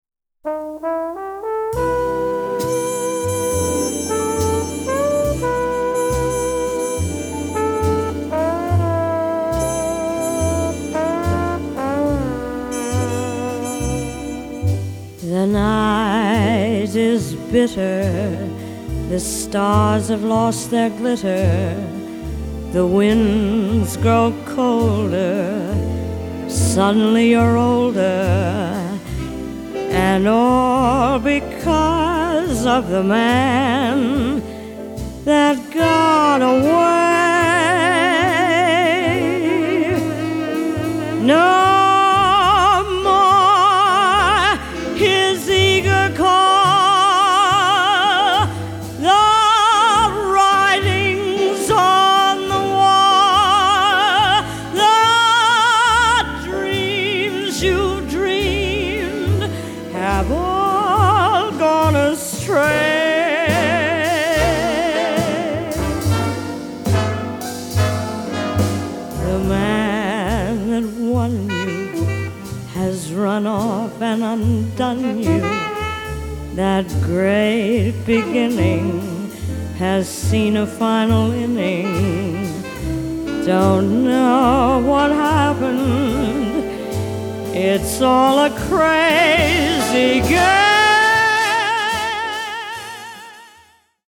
† Newly Upgraded Stereo